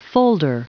Prononciation du mot folder en anglais (fichier audio)